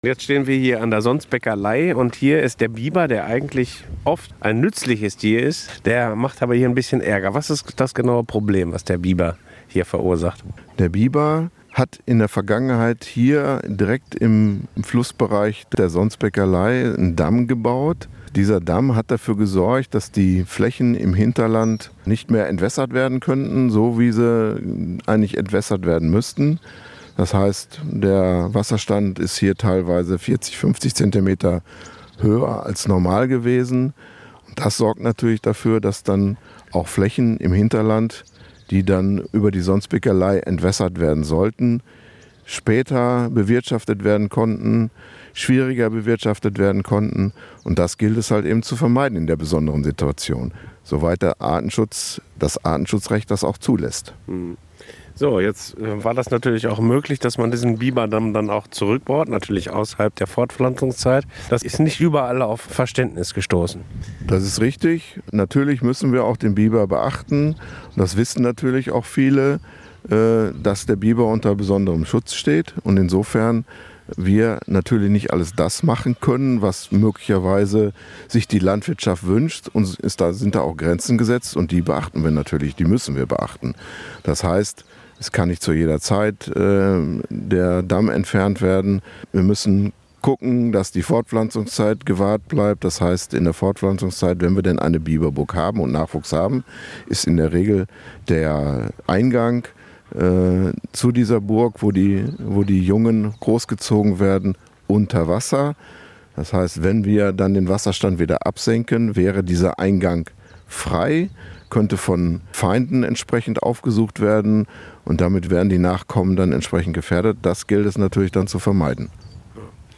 interview-hp-biber-sonsbecker-ley.mp3